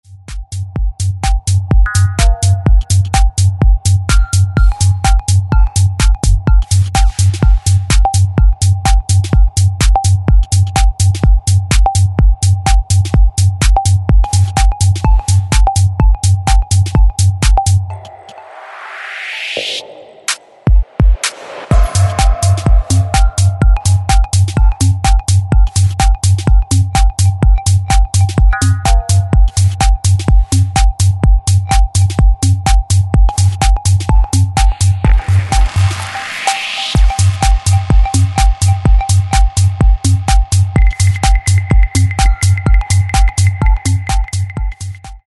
Styl: Techno, Minimal